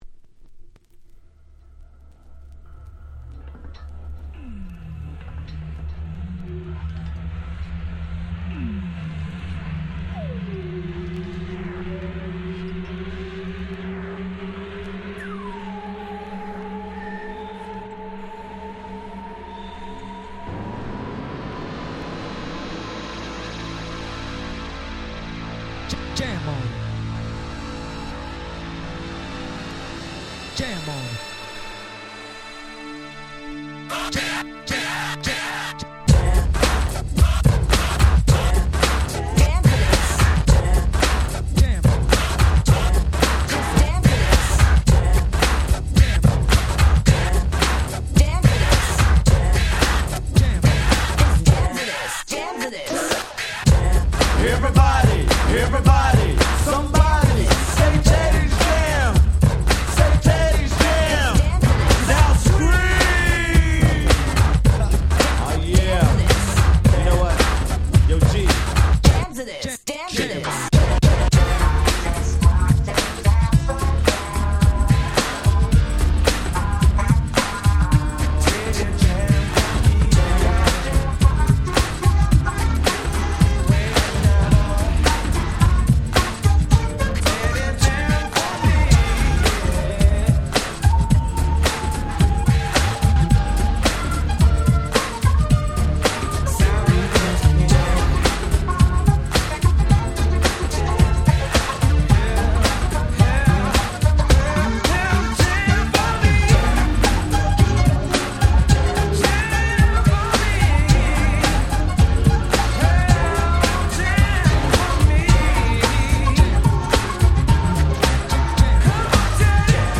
言わずと知れた最高のNew Jack Swing !!